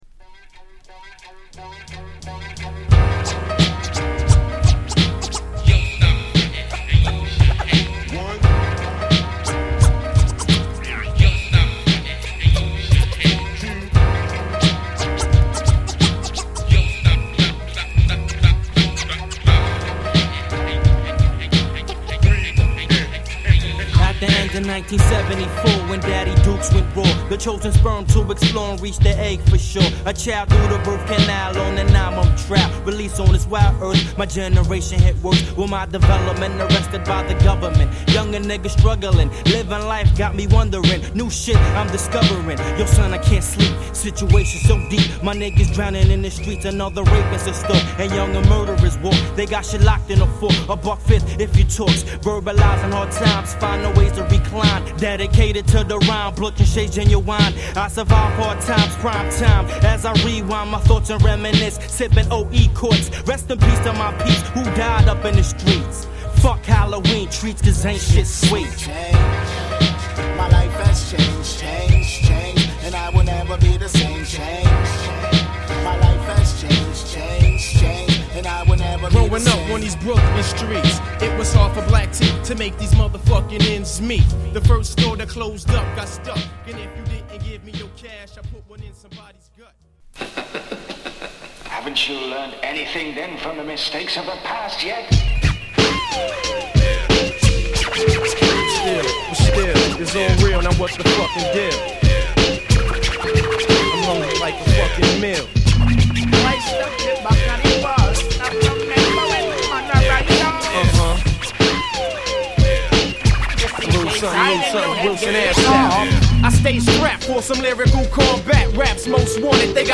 クールで美しいトラックが堪らない、文句無しのUnderground Hip Hopクラシック！